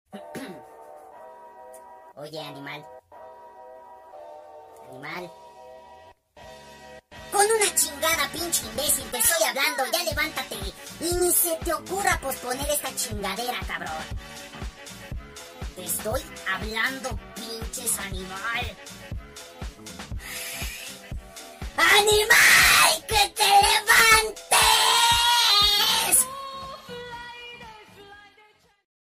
Tono Para Alarma | Tono Sound Effects Free Download